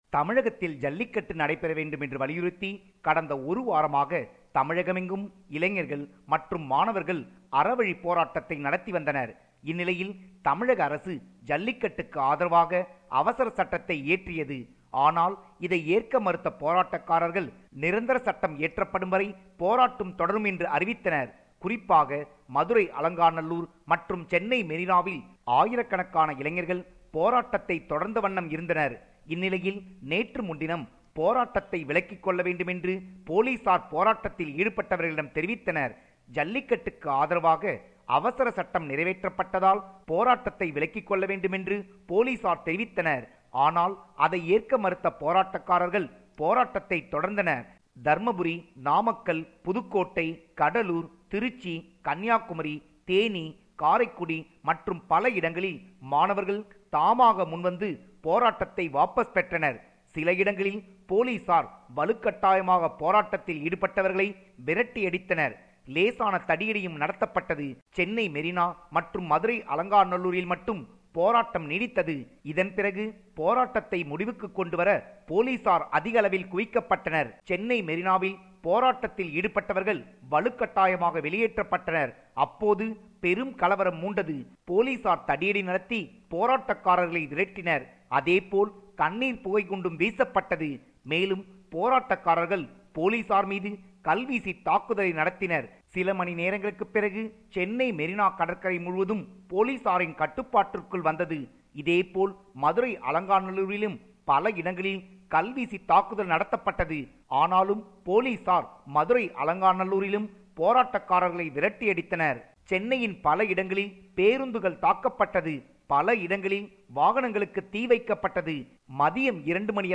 compiled a report focusing on major events/news in Tamil Nadu